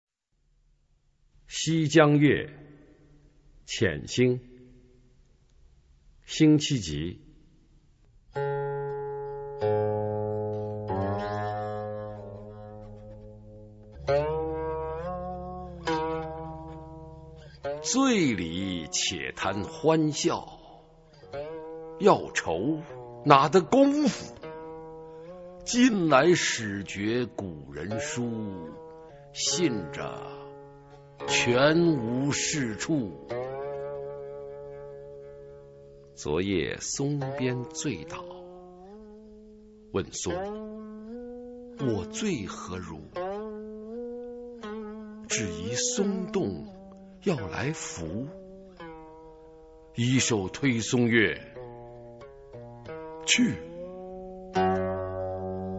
[宋代诗词诵读]辛弃疾-西江月·遣兴（男） 宋词朗诵